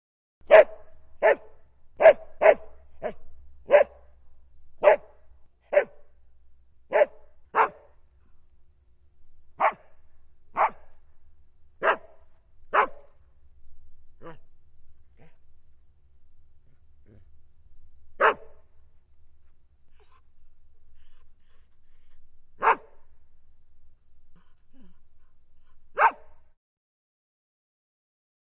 Dog, Border Collie Barks. Reverberant, Low Pitched, Throaty Barks With A Light Cricket Background. Medium Perspective.